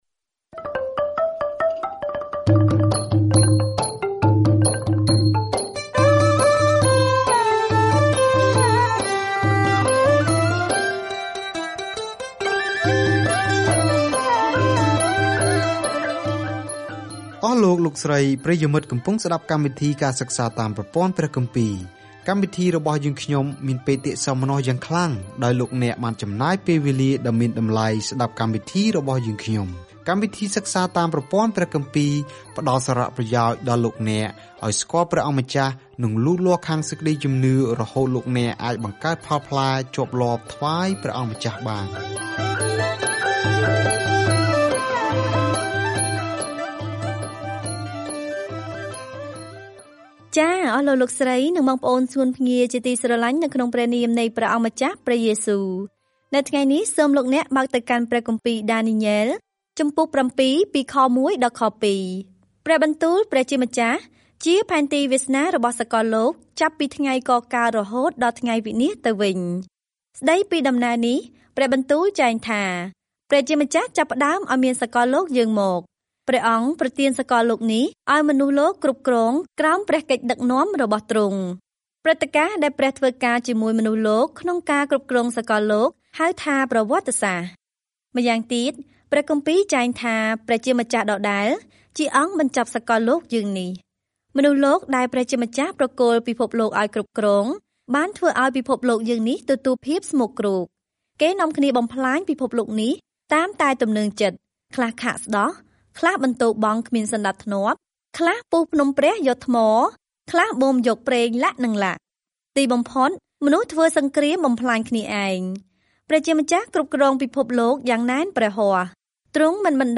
សៀវភៅដានីយ៉ែលគឺជាសៀវភៅជីវប្រវត្តិរបស់បុរសម្នាក់ដែលបានជឿព្រះ និងជាទស្សនវិស័យព្យាករណ៍អំពីអ្នកដែលនឹងគ្រប់គ្រងពិភពលោកនៅទីបំផុត។ ការធ្វើដំណើរជារៀងរាល់ថ្ងៃតាមរយៈដានីយ៉ែល នៅពេលអ្នកស្តាប់ការសិក្សាជាសំឡេង ហើយអានខគម្ពីរដែលជ្រើសរើសពីព្រះបន្ទូលរបស់ព្រះ។